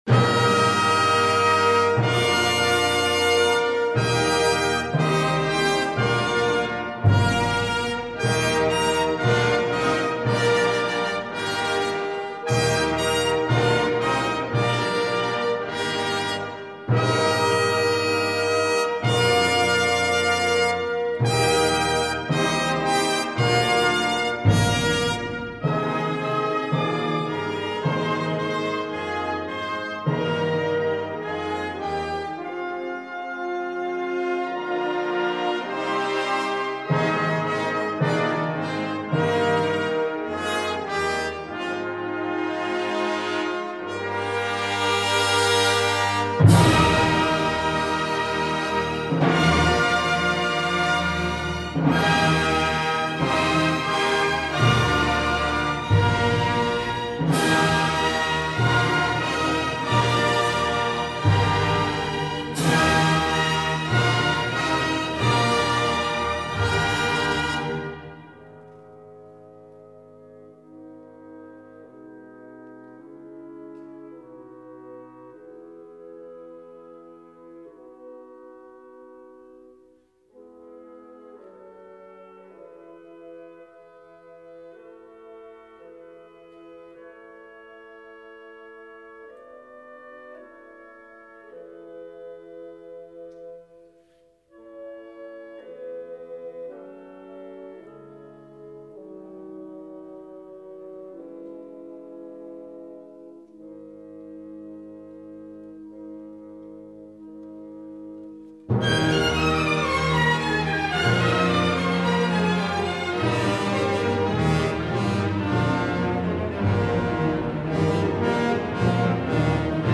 mp3 (13.9 MiB) М.П. Мусоргский Богатырские ворота. Симф. орк.